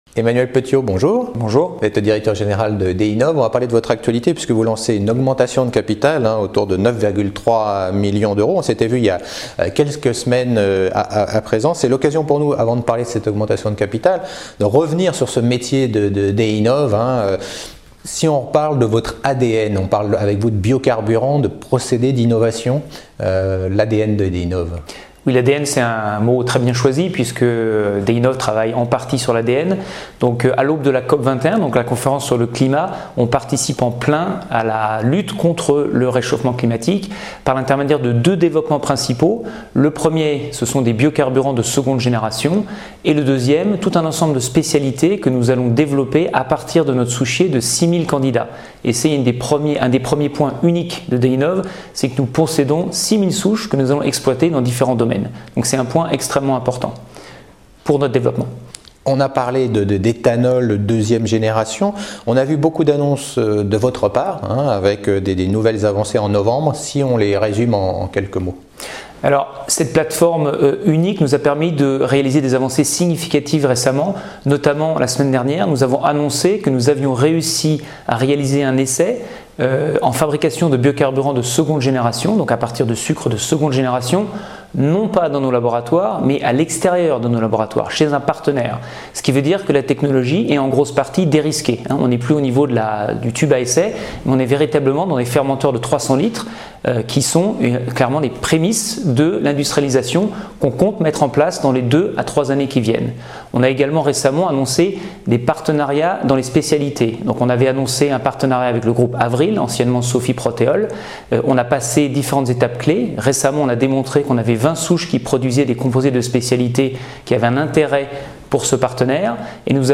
Au sommaire de l’interview